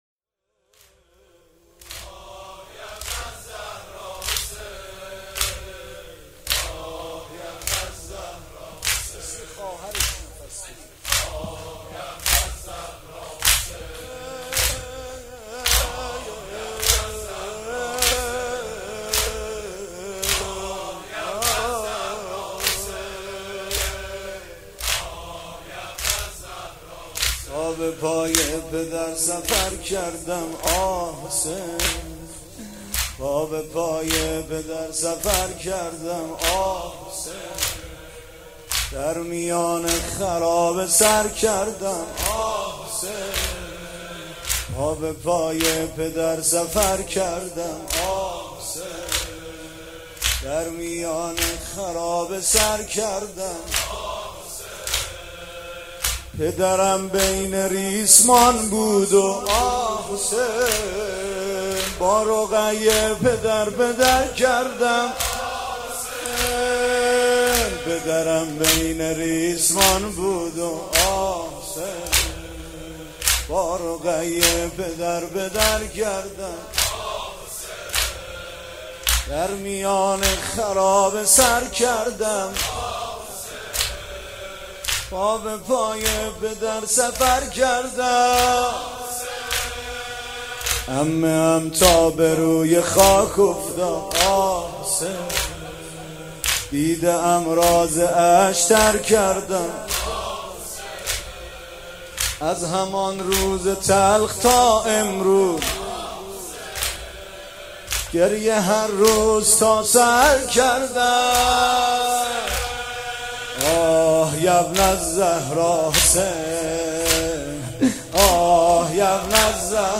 فایل صوتی نوحه وفات حضرت سکینه (س)